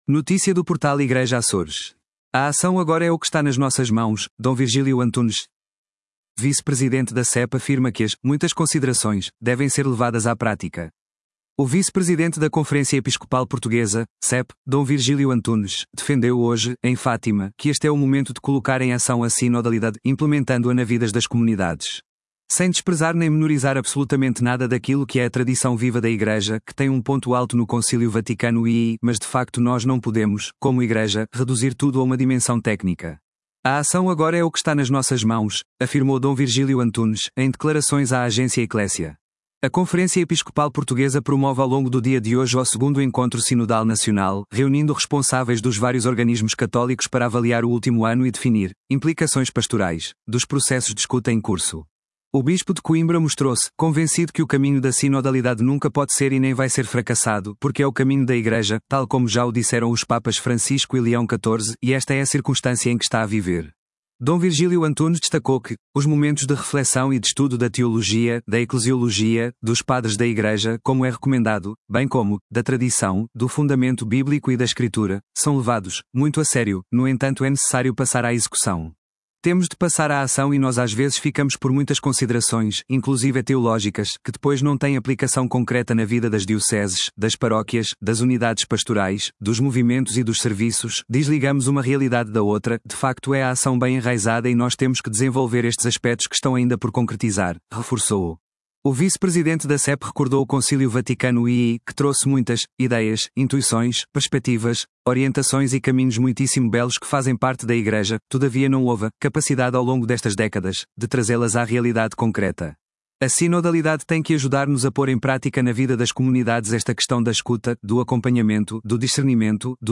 O vice-presidente da Conferência Episcopal Portuguesa (CEP), D. Virgílio Antunes, defendeu hoje, em Fátima, que este é o momento de colocar em ação a sinodalidade, implementando-a na vidas das comunidades.
“Sem desprezar nem menorizar absolutamente nada daquilo que é a tradição viva da Igreja, que tem um ponto alto no Concílio Vaticano II, mas de facto nós não podemos, como Igreja, reduzir tudo a uma dimensão técnica. A ação agora é o que está nas nossas mãos”, afirmou D. Virgílio Antunes, em declarações à Agência Ecclesia.